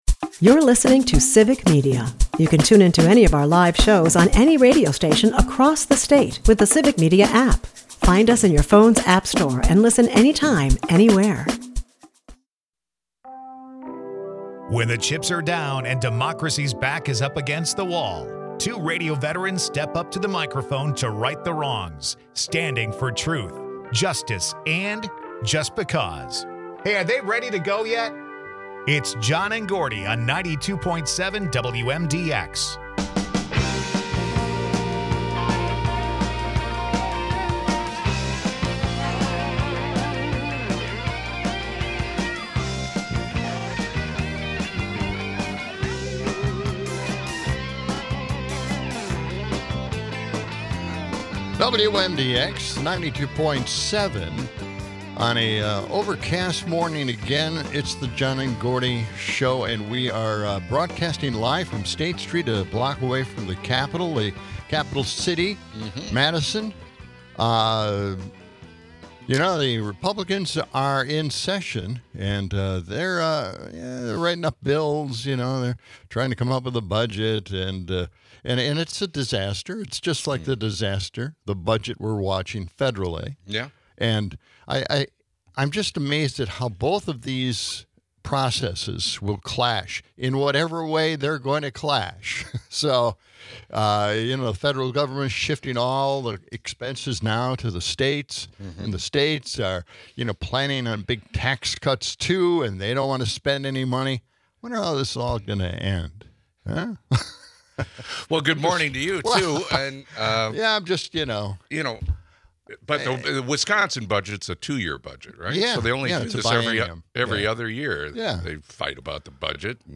They discuss how shifting costs burden states while federal budgets are at a stalemate. The duo also banters about odd traditions like the Chili Willy race and the dangers of flip flops. Later, they dive into a critique of Republican policies, the implications of the big bad bill, and the troubling cuts to Medicaid.